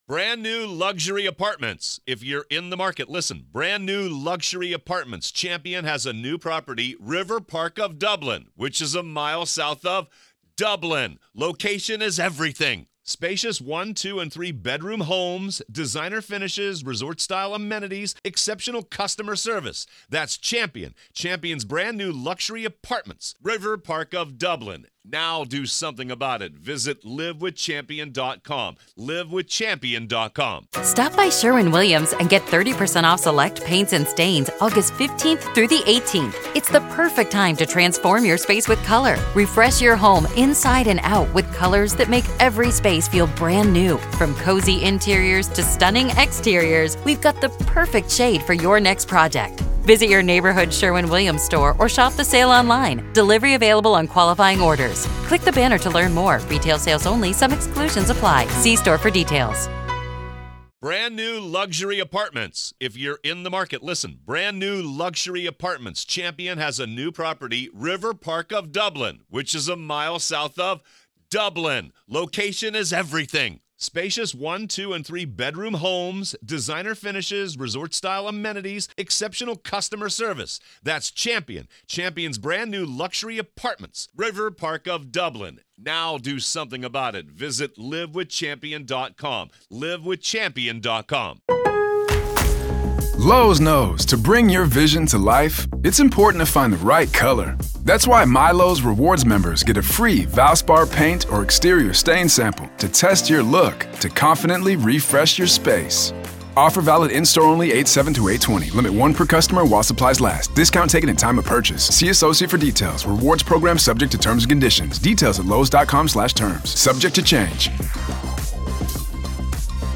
retired FBI Special Agent and Hidden Killers Daily Contributor